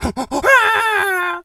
pgs/Assets/Audio/Animal_Impersonations/monkey_hurt_scream_05.wav at master
monkey_hurt_scream_05.wav